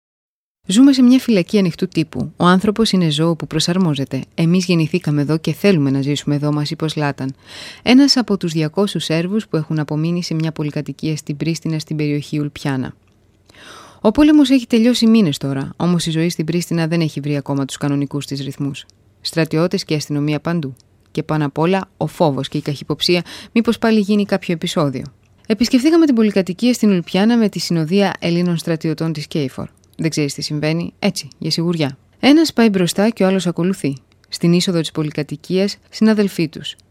griechische Sprecherin für Werbung, Dokumentationen, Voice Over, TV, Radio uvm.
Kein Dialekt
Sprechprobe: Werbung (Muttersprache):
greece female voice over artist